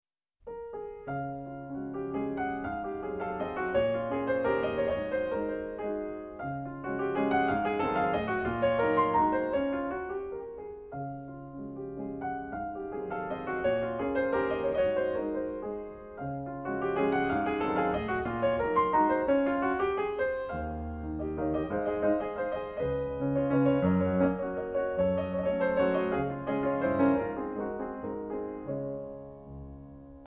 Pianist
refined, patrician touch